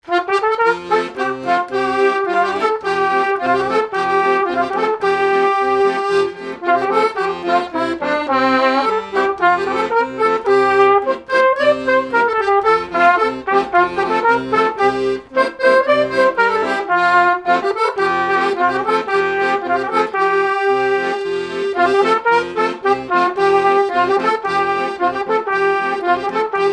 danse : menuet congo
airs de danses issus de groupes folkloriques locaux
Pièce musicale inédite